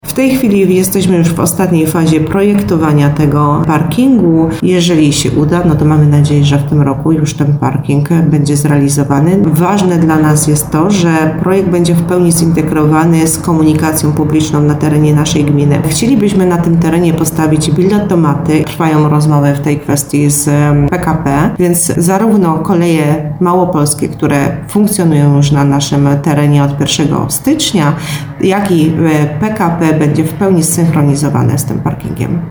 -mówi wójt Małgorzata Moskal.